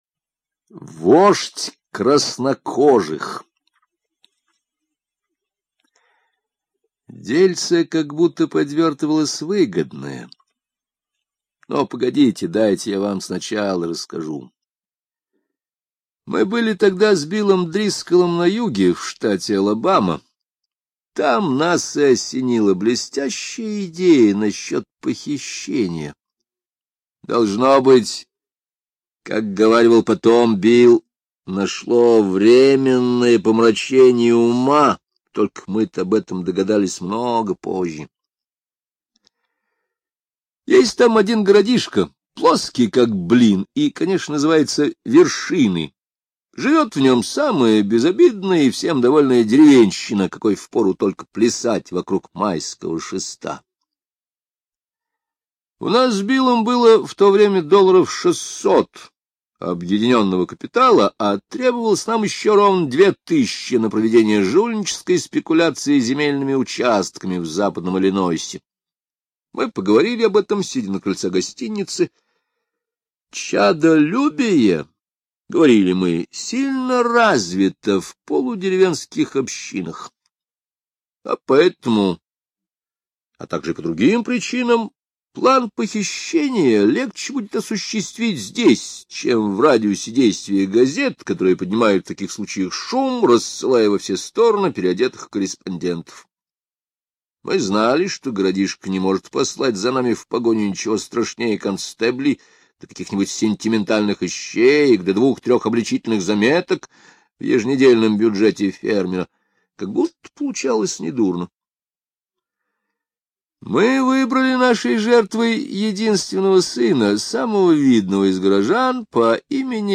Аудиокнига Вождь краснокожих
Качество озвучивания весьма высокое.